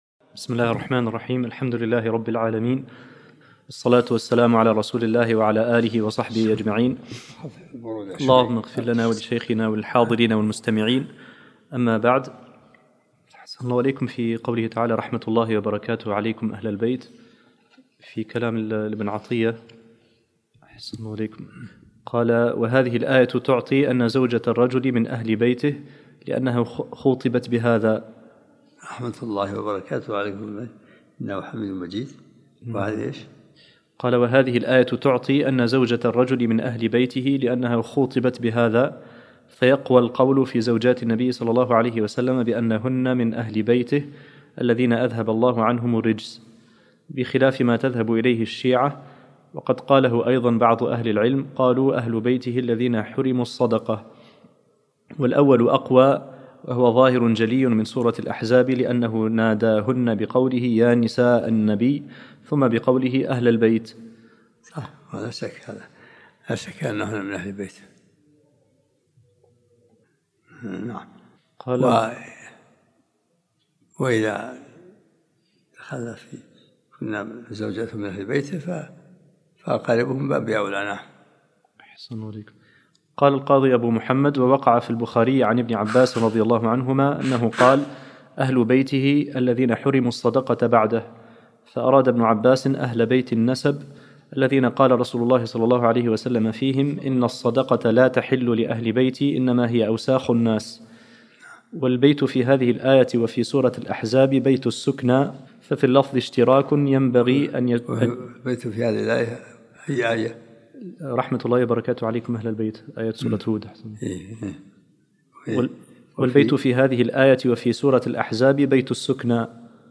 الدرس الخامس من سورة هود